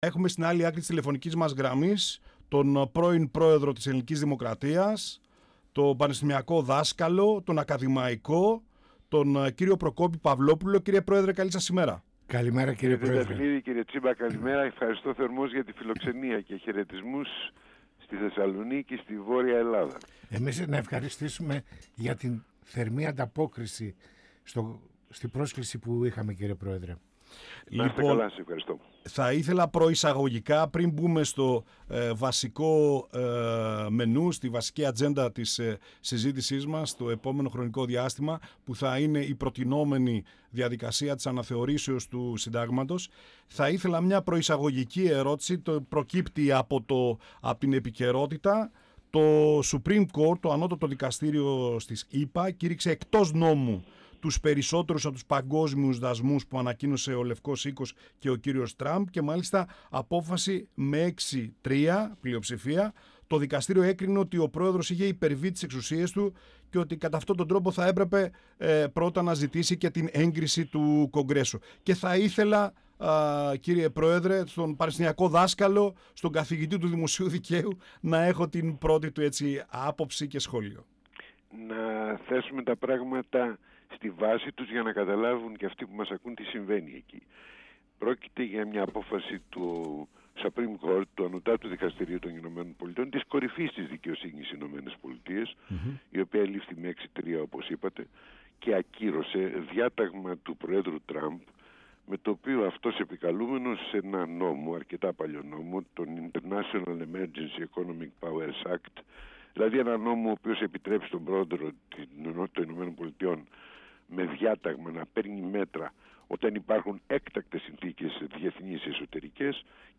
Ο πρώην Πρόεδρος της Ελληνικής Δημοκρατίας Προκόπης Παυλόπουλος στον 102FM της ΕΡΤ3 | «Πανόραμα Επικαιρότητας» | 21.02.2026